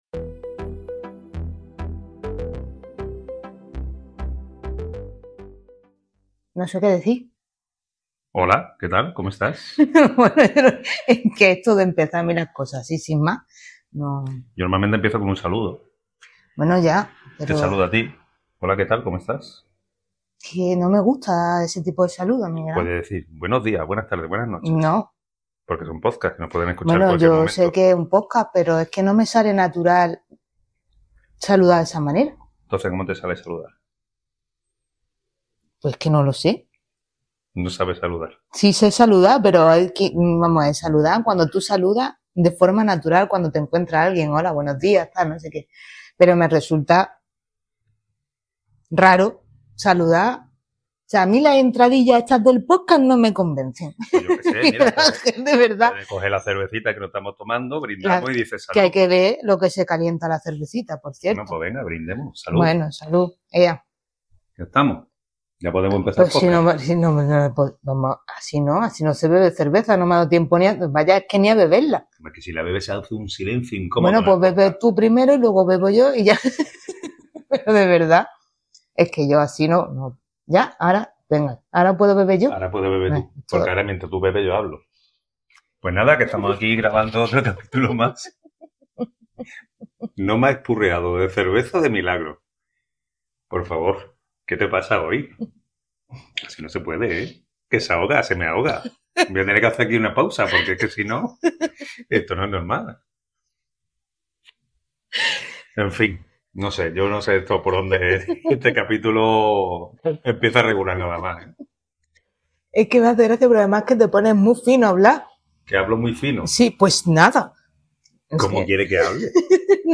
Entre anécdotas, ruidos de ciudad, consejos para no jugársela ni en el mar ni en la montaña, y nuestro fiel Momento Vermú de la semana, te servimos un capítulo fresquito y con chispa, donde lo único que falta es la tapa gratis.